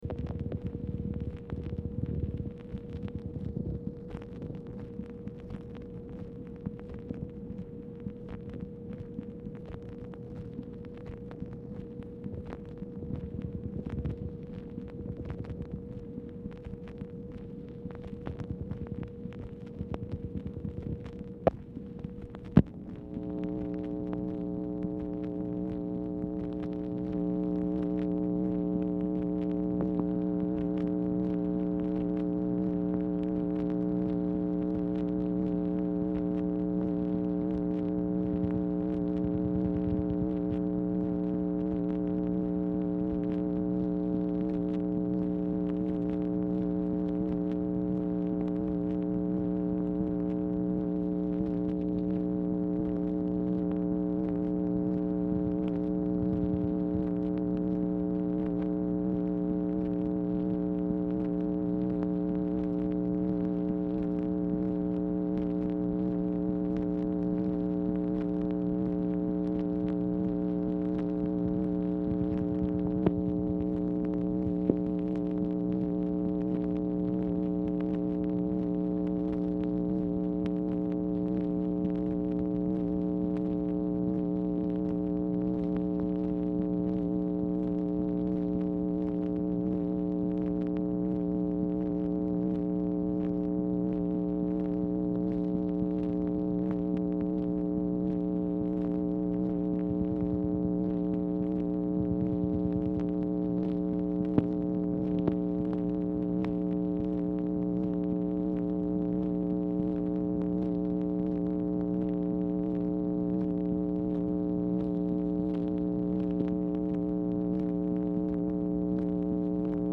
Telephone conversation # 8670, sound recording, MACHINE NOISE, 8/30/1965, time unknown | Discover LBJ
Format Dictation belt